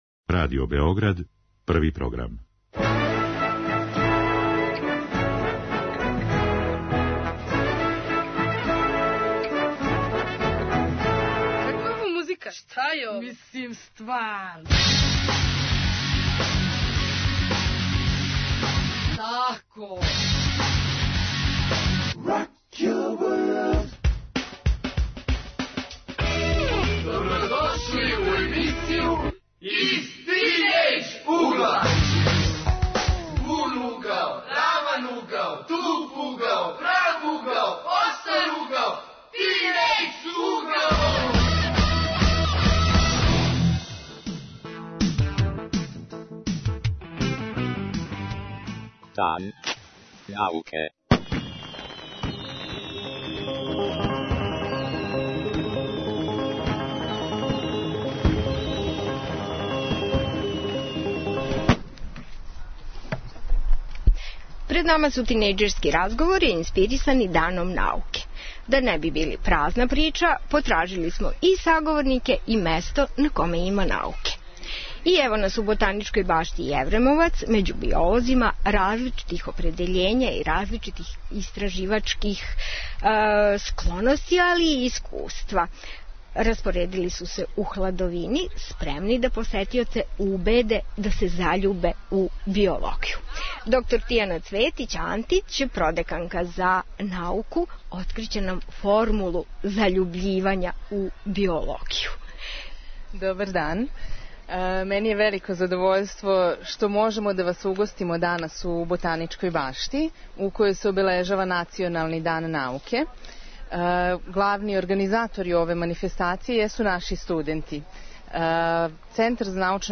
Разговори поводом Дана науке – уживо из Ботаничке баште